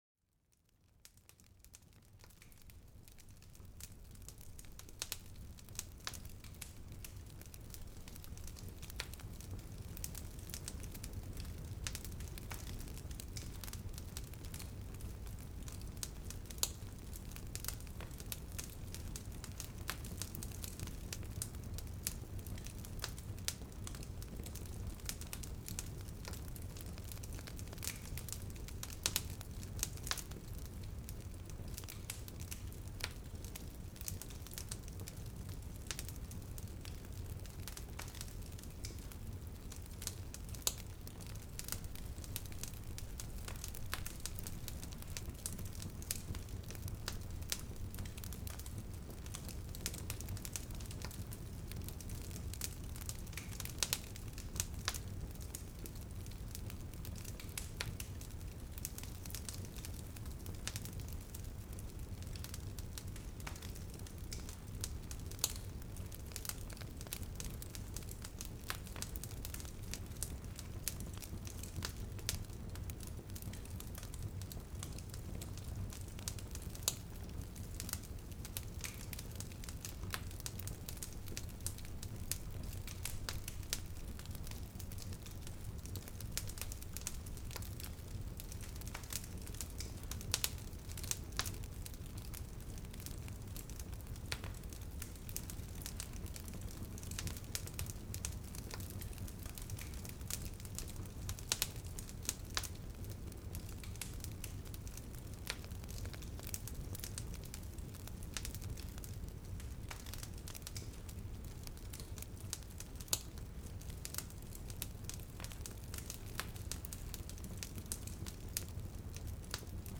Calidez Relajante: Relájate con el Sonido Confortante de la Chimenea
Sumérgete en el ambiente reconfortante de una chimenea crepitante, donde los sonidos calmantes te envuelven en una manta de calor. Ideal para una noche de relajación, este episodio ofrece una escapada sonora que calma la mente y relaja el cuerpo.